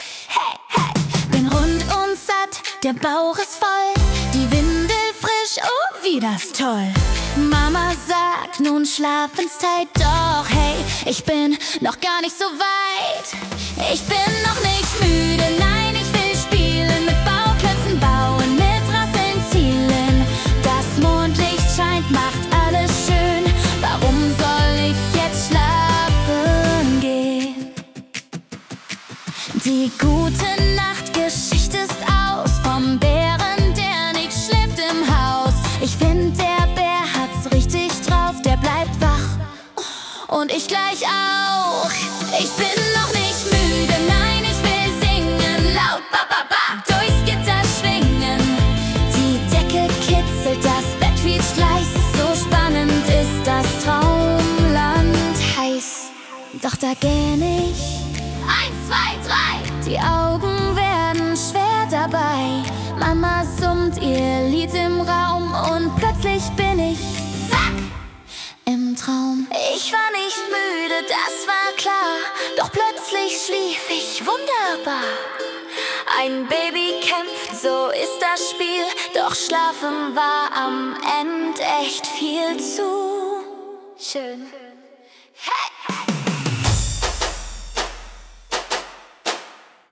Genre: Pop
Vocal: KI (Suno Premier)
ist ein humorvoller, charmant verspielter Song